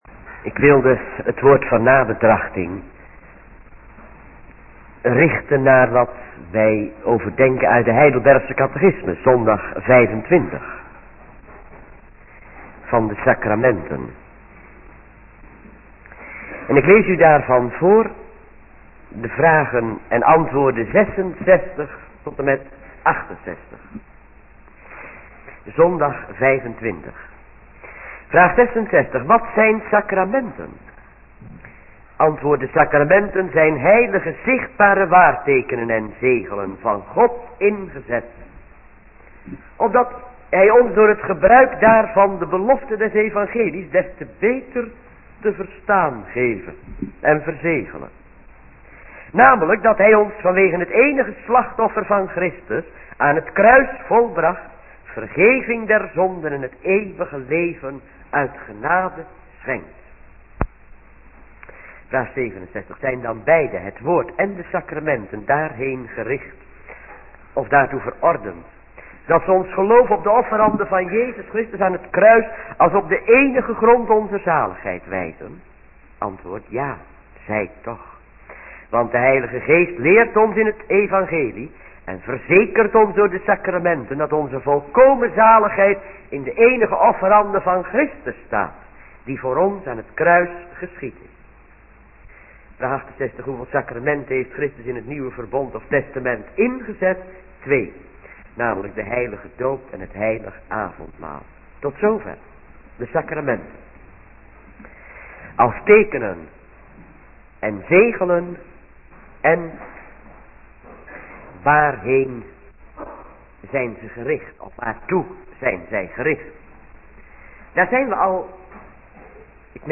middagdienst -